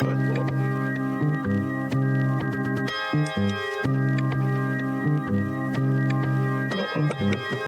Melody